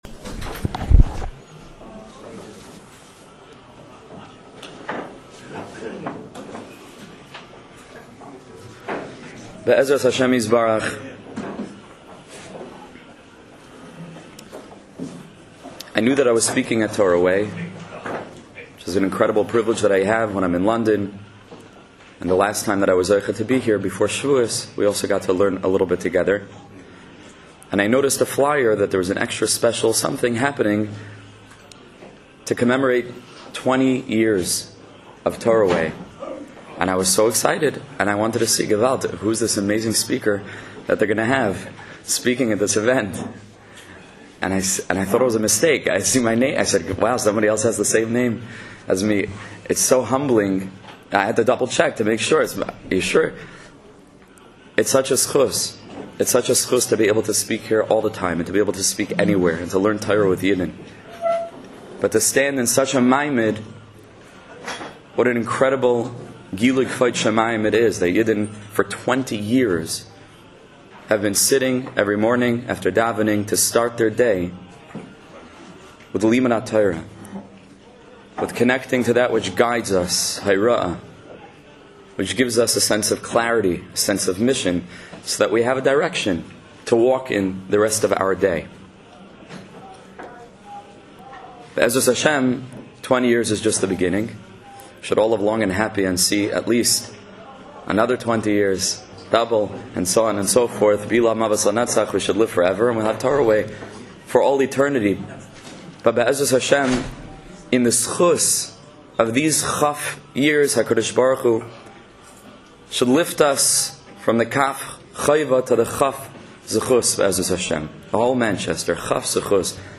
Start Your Day The TorahWay Manchester provides daily shiurim on a wide range of topics.
20th Anniversary Event